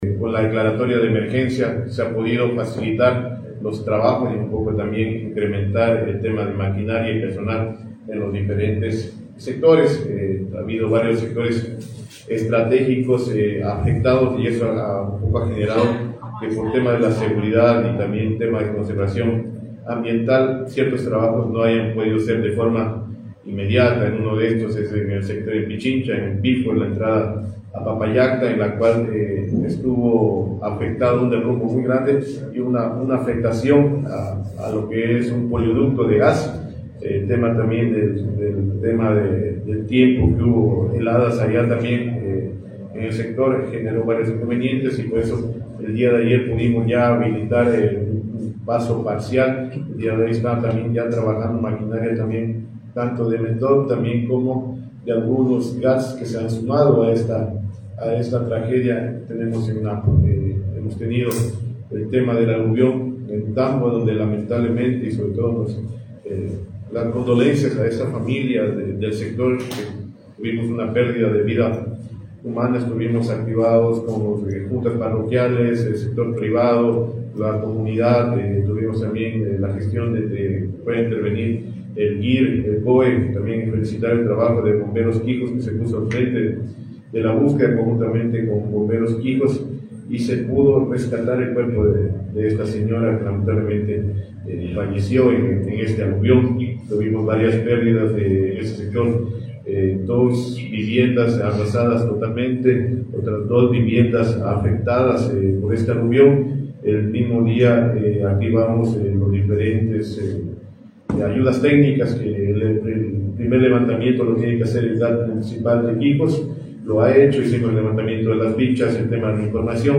Este día 7 de julio de 2025, Gary Rivadeneyra, gobernador de Napo, junto a Fabián Pulla, subsecretario del MTOP, informaron que gracias a la declaratoria de emergencia en la zona, permite priorizar los trabajos en todos los frentes.